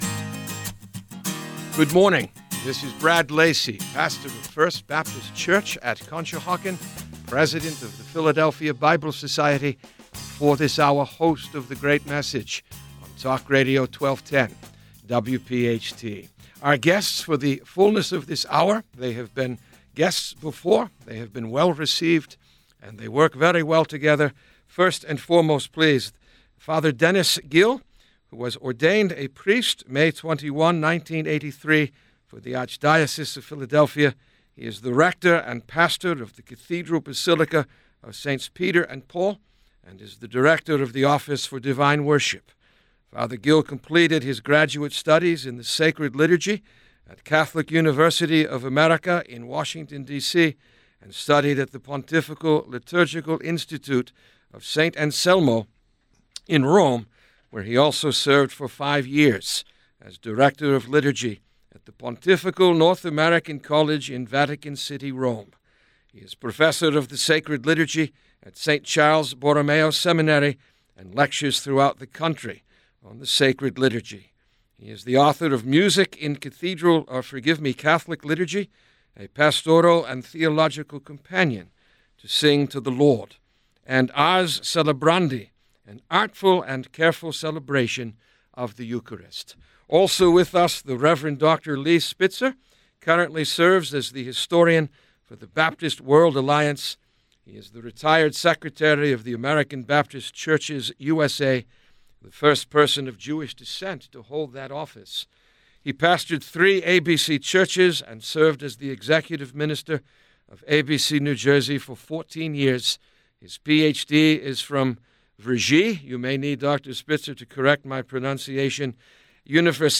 A Catholic-Protestant Conversation